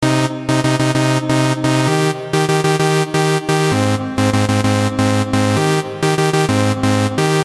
锯齿合成器1
描述：用锯齿振荡器制作的合成器循环，包括基于三度的几个谐波
Tag: 130 bpm Dance Loops Synth Loops 1.25 MB wav Key : Unknown